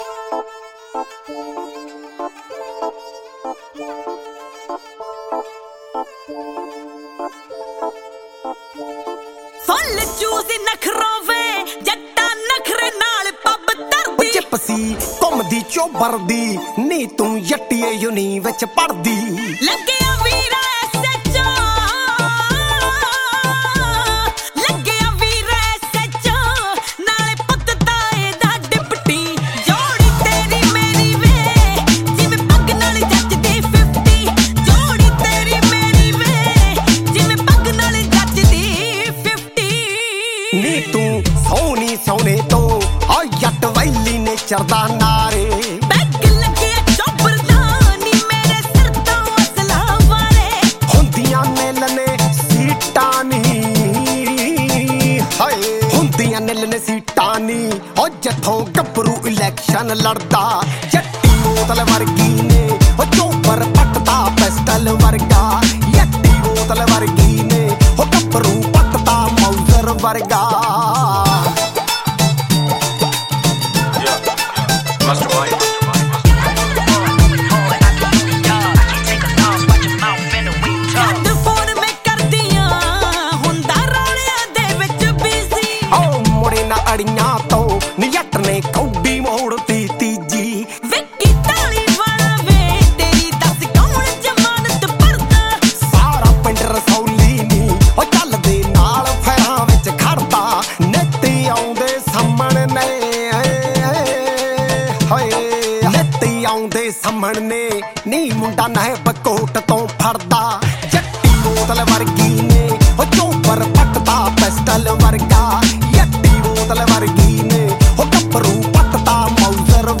Punjabi